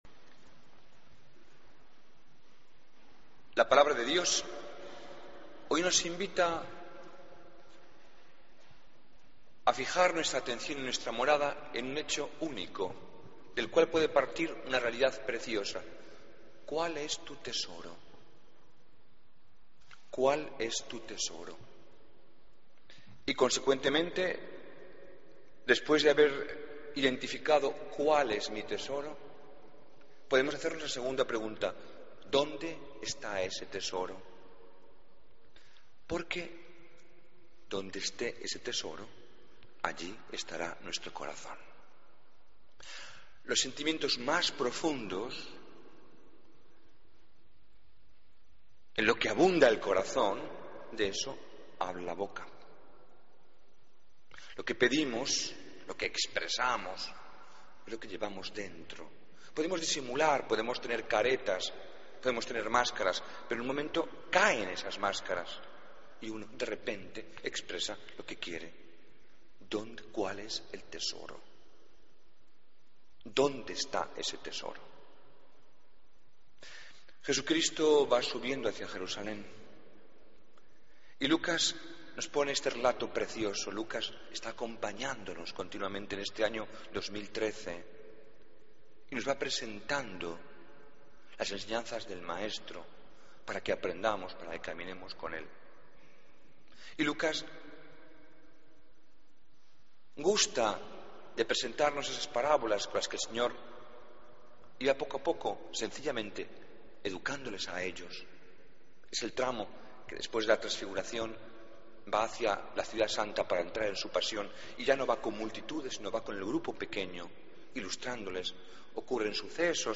Homilía del 11 de agosto de 2013